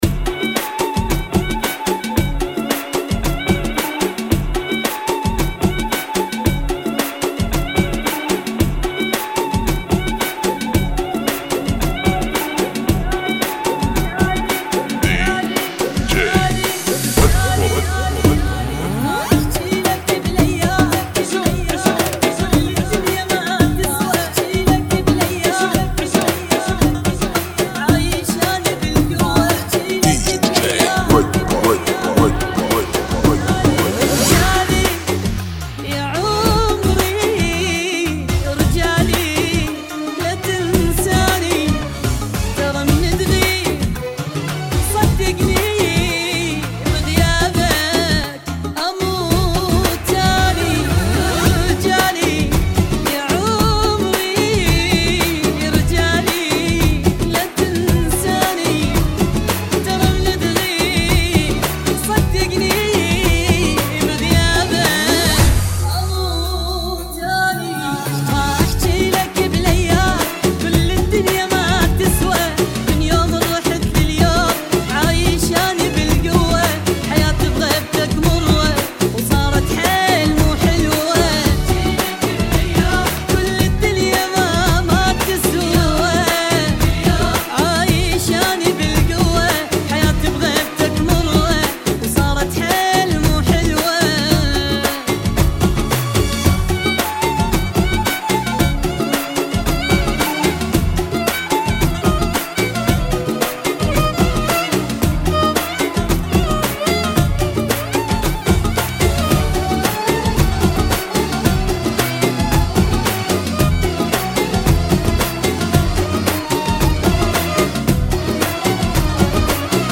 [ 90 bpm ]